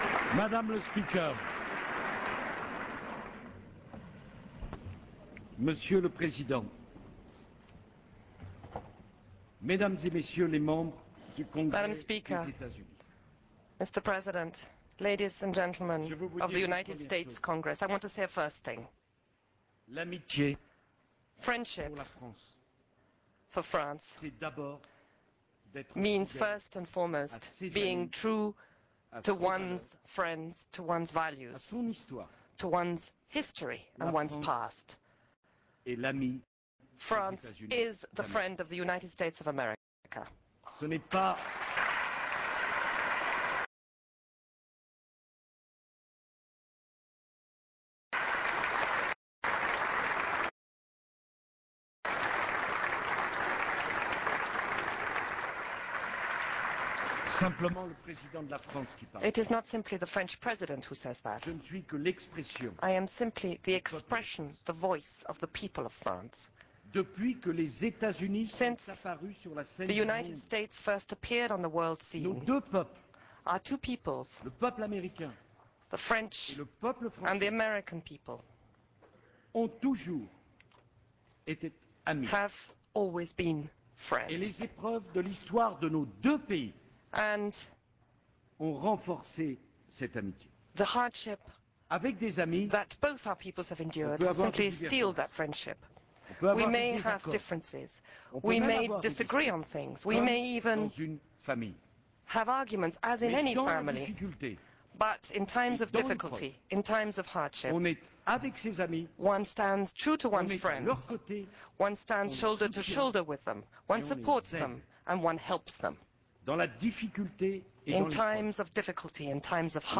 Sarkozy’s Speech to Congress